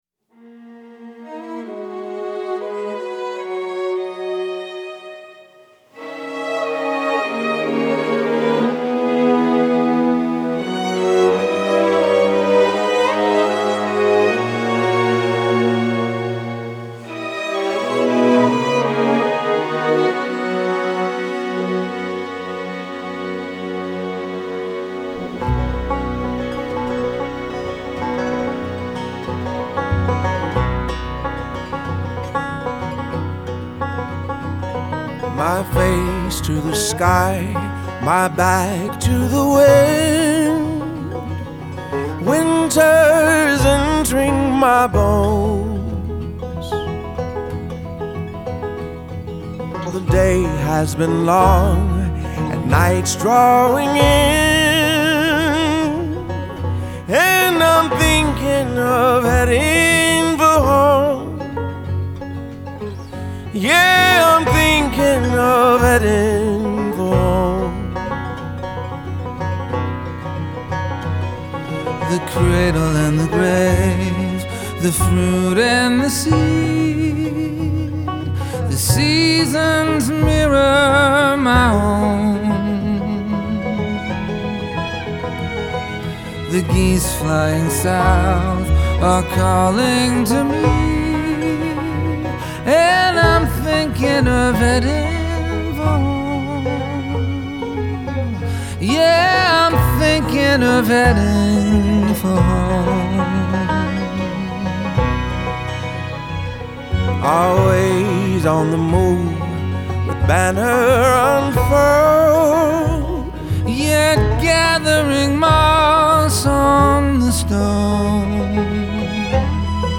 Genre : Folk